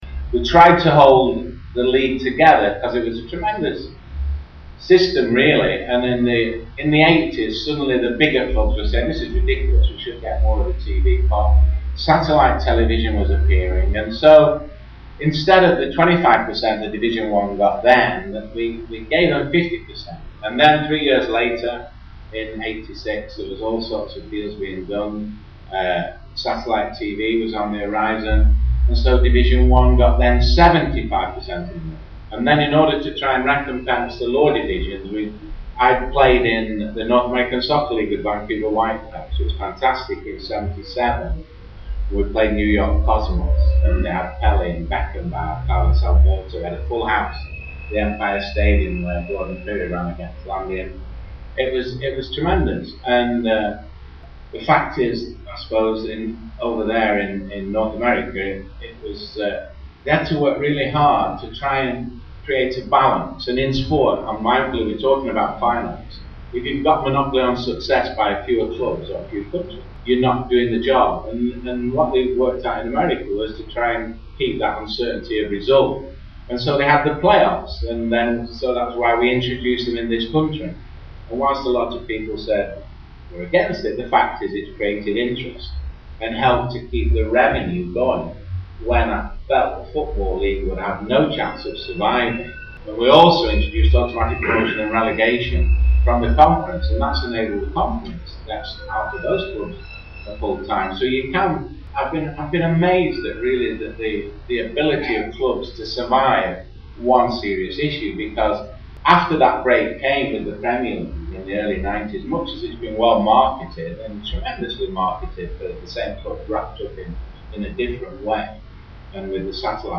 Football Finance. Gordon Taylor speaks about the development of the game from the 80’s to present. Recorded at the Midland Hotel, TUC Congress 2010